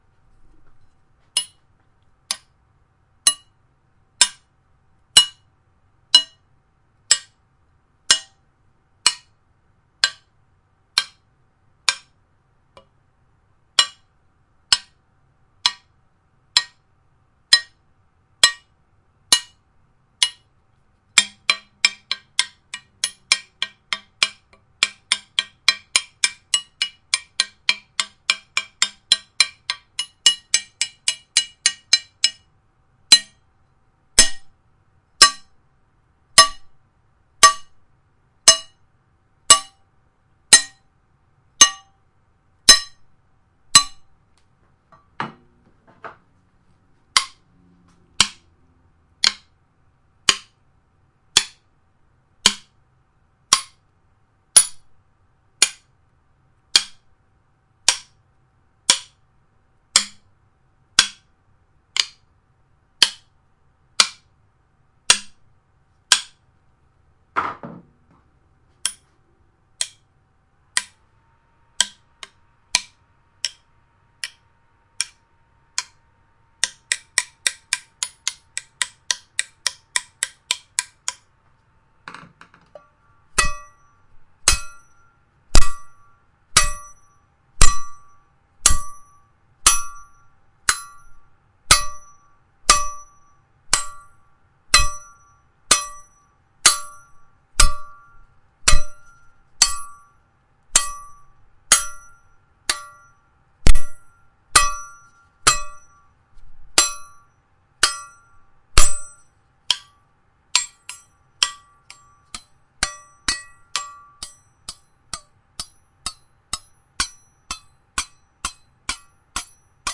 车库工具，废品 " 撬棍式螺丝刀
描述：用槽螺丝刀敲击和刮削金属撬棍。
标签： 撬棍 罢工 锣鼓 金属 螺丝刀 金属 命中 工业 冲击
声道立体声